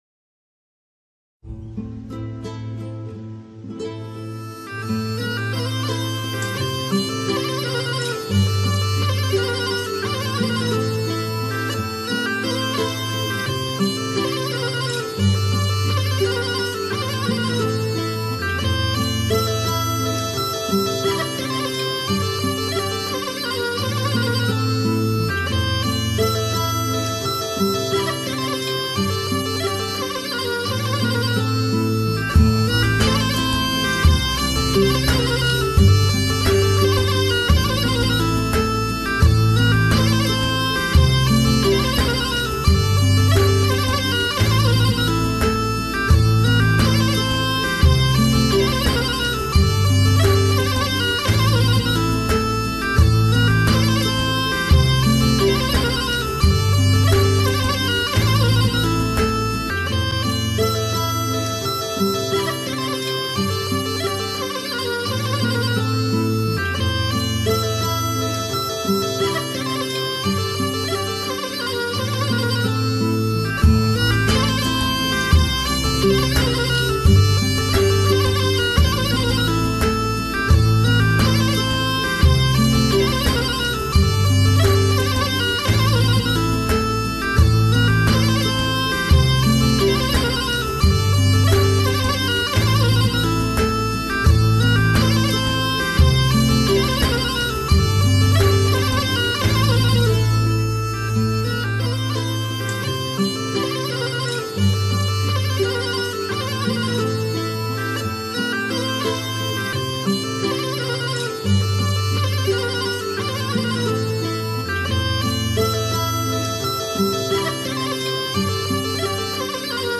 Karadeniz Türküsü: Tulum
Tulum eşliğinde enstrümantal bir türkü.
tulum-enstrumantal.mp3